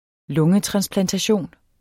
Udtale [ ˈlɔŋə- ]